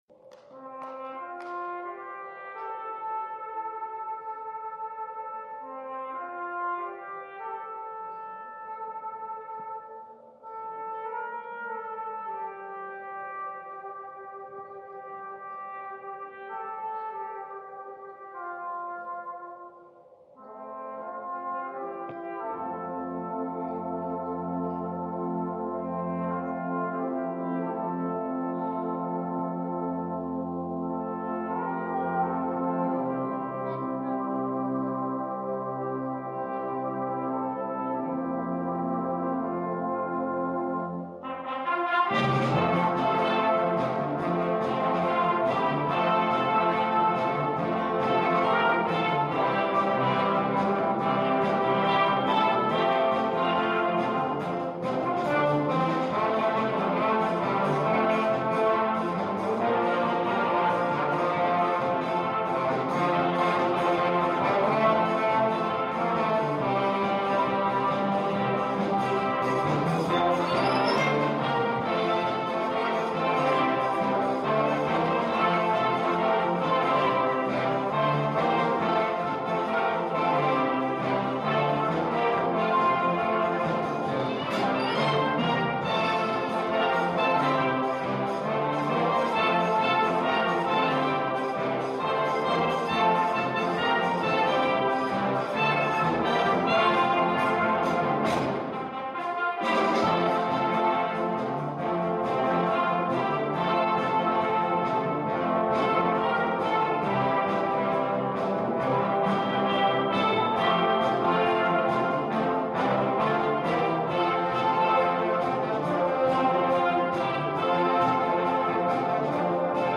2 _ facile _einfach _ Easy
Ensemble 6-7-8 voix Flex
Easy Listening / Unterhaltung / Variété